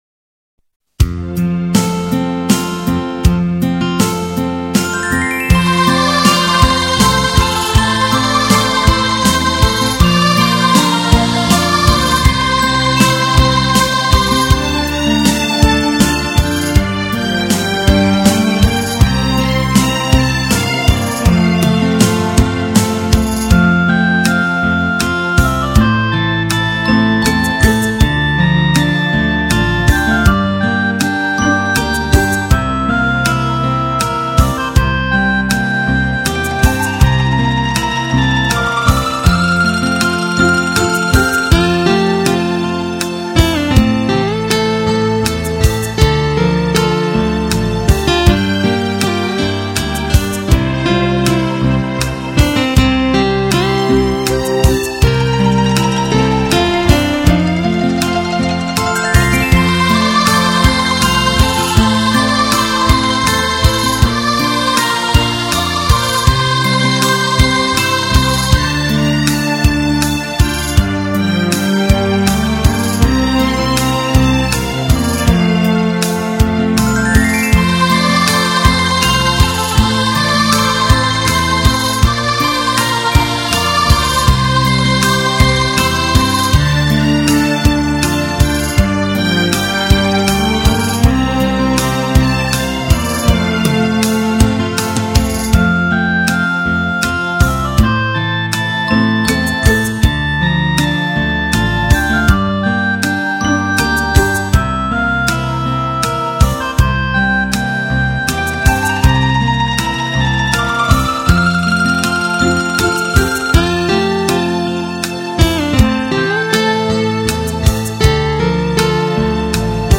（中三）